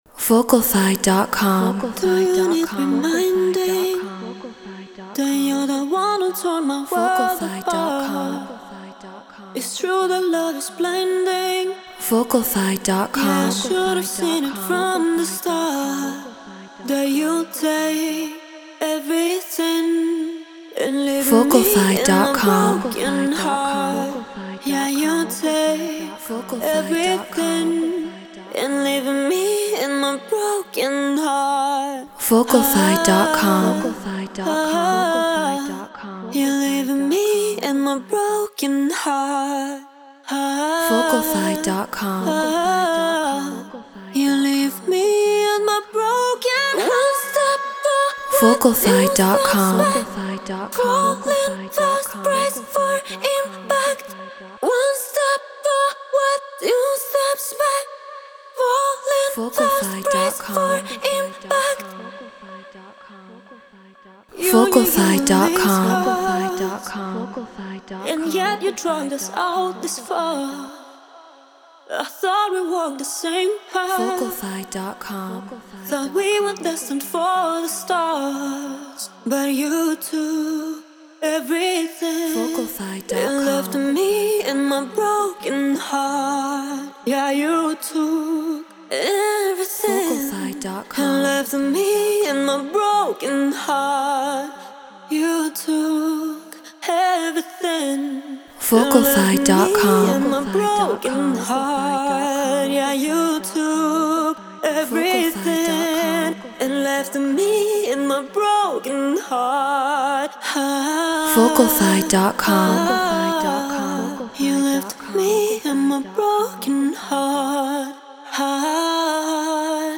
Non-Exclusive Vocal.